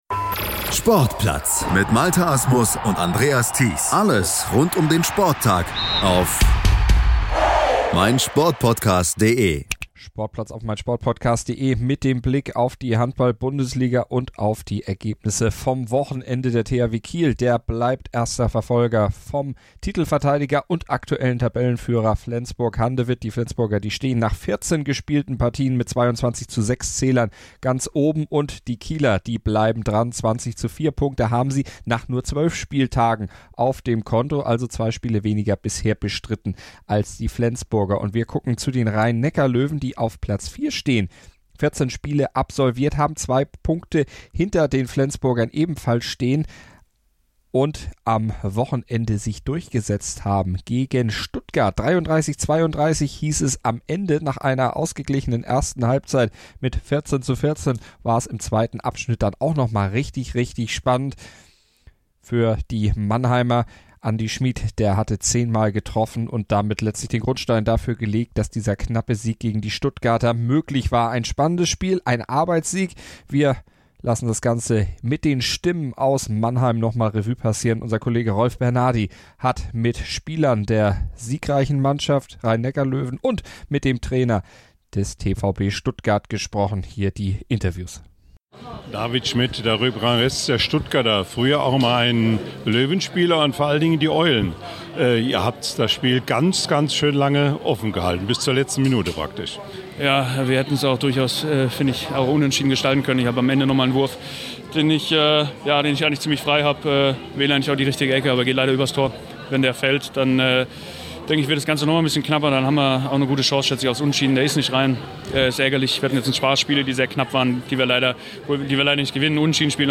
Handball Podcast
stimme-zum-zittersieg-der-loewen.mp3